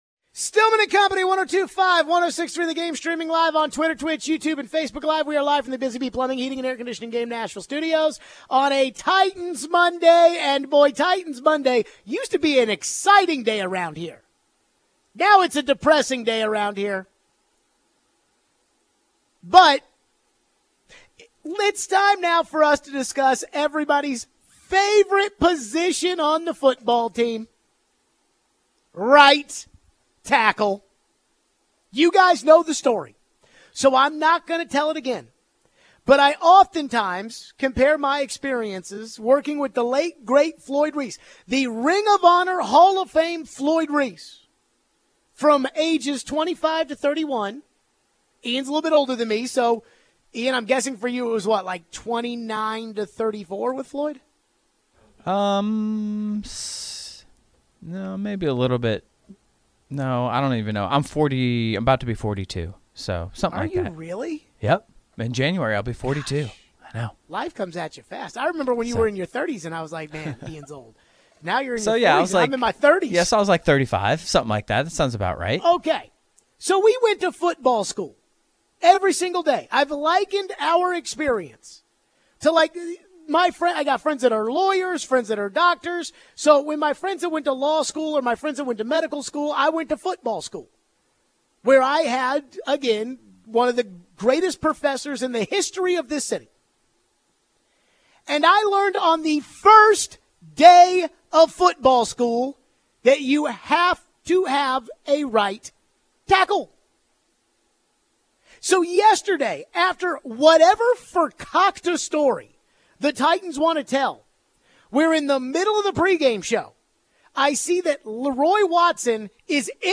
Taking your calls on the Titans.